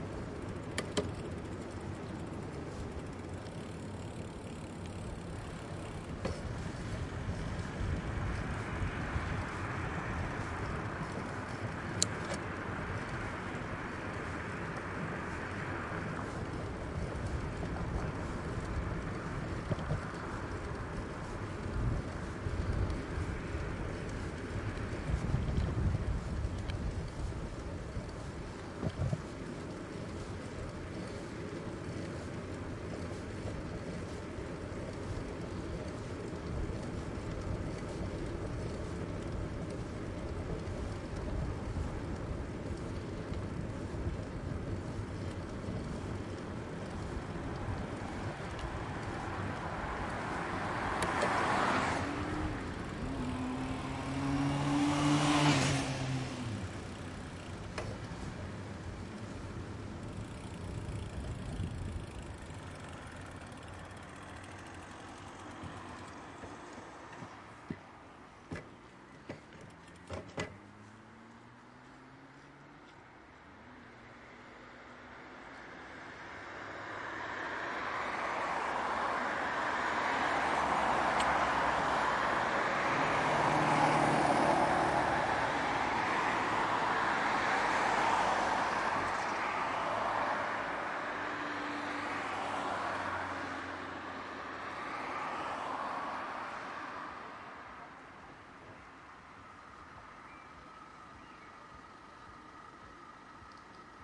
自行车的声音
描述：在校园骑自行车的同时，当我沿着公路行驶时，我会换档并等待交通通过。
标签： 城市 交通 街道 汽车 现场录音 自行车
声道立体声